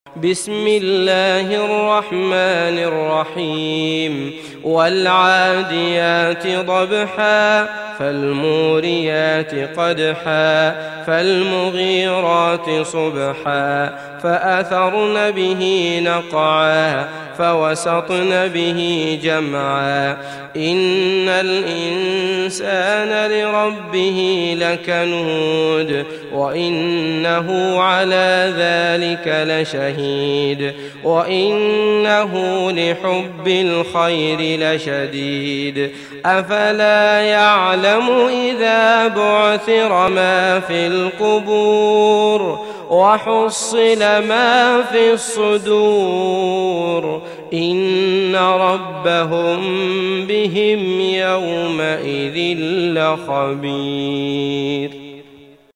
تحميل سورة العاديات mp3 بصوت عبد الله المطرود برواية حفص عن عاصم, تحميل استماع القرآن الكريم على الجوال mp3 كاملا بروابط مباشرة وسريعة